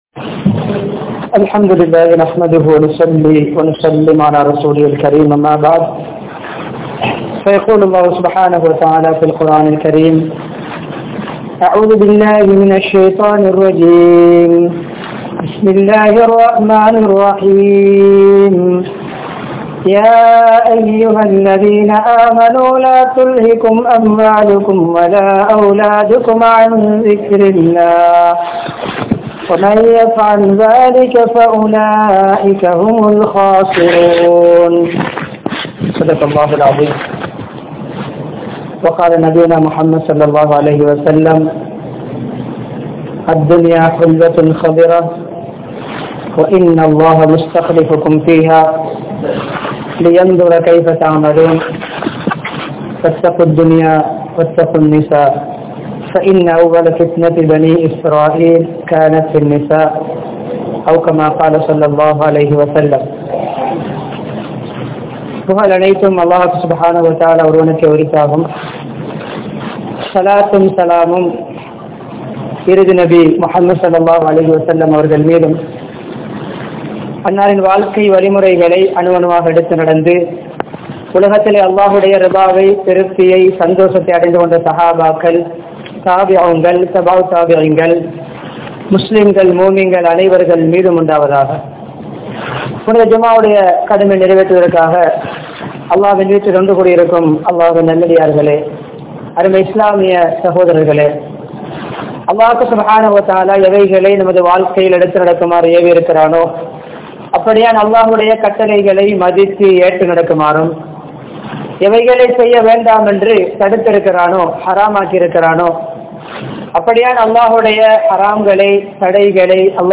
How to Use Your Moblie Phone | Audio Bayans | All Ceylon Muslim Youth Community | Addalaichenai
Elamalpotha, Majmaulkareeb Jumuah Masjith